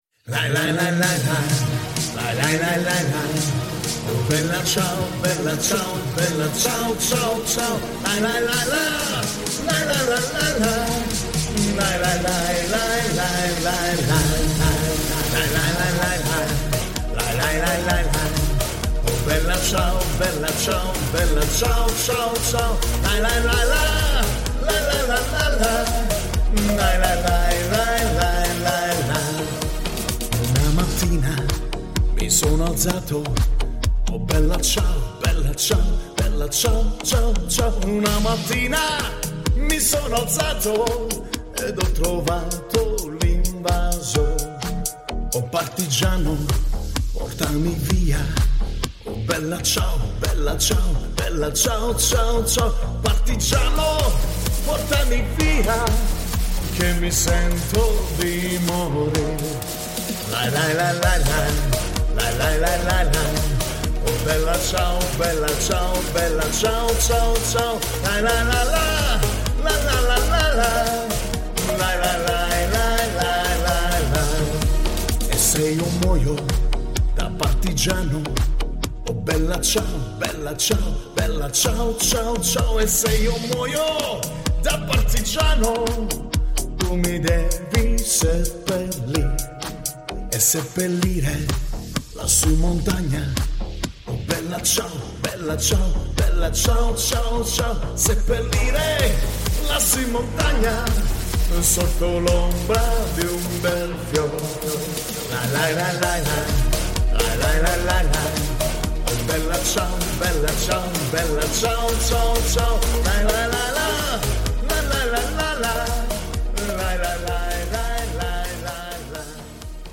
Live Musik | Italienische Musik